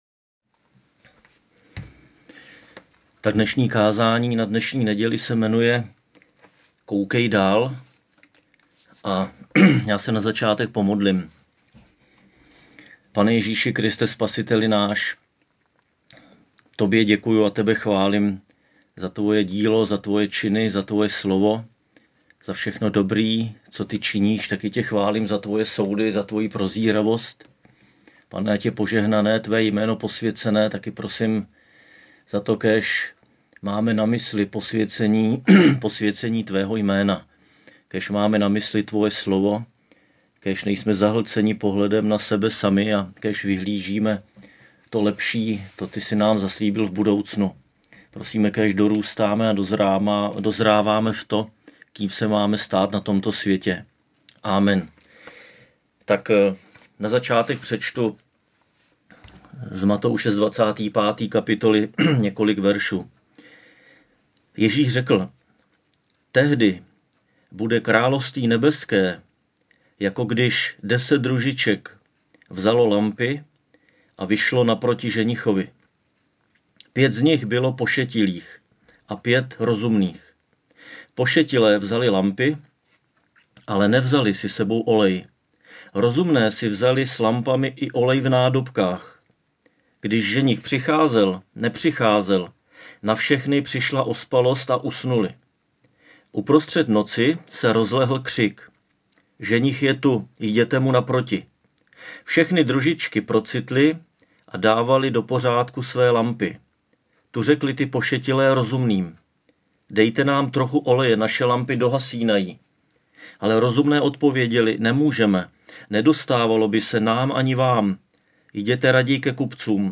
Křesťanské společenství Jičín - Kázání 8.11.2020